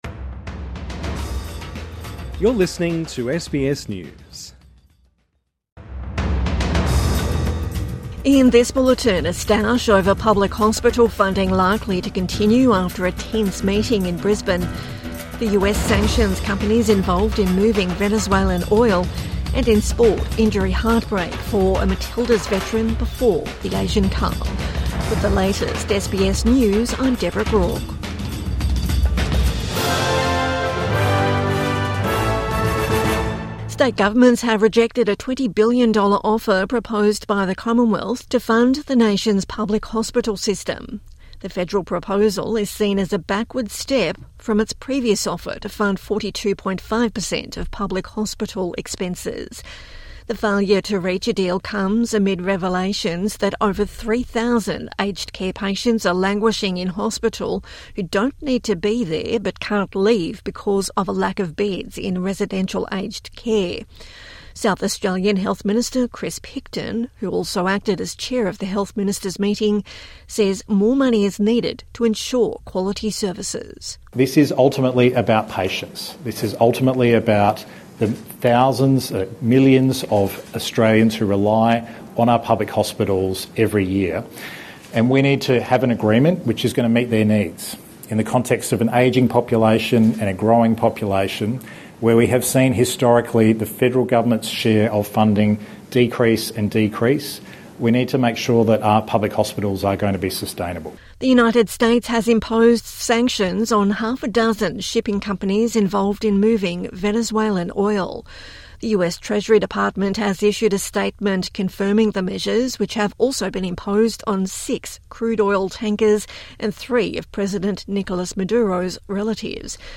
States to continue push for more public hospital money | Evening News Bulletin 12 December 2025